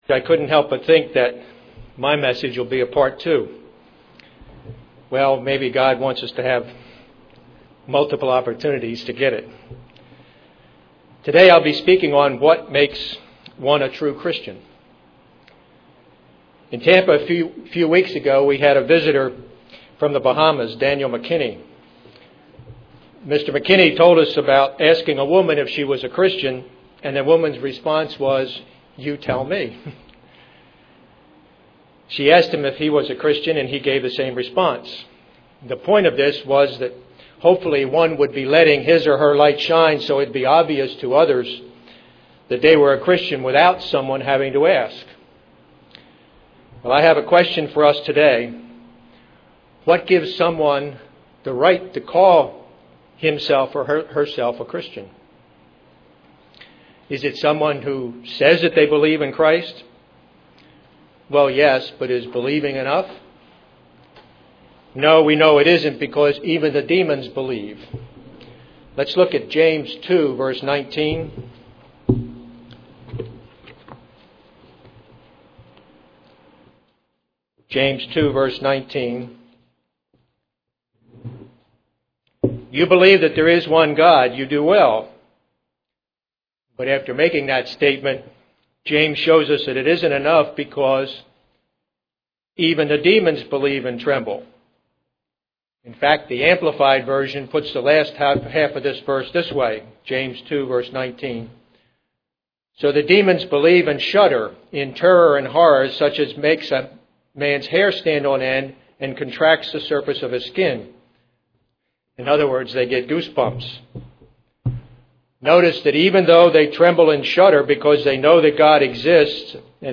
Given in Ft. Lauderdale, FL
UCG Sermon Studying the bible?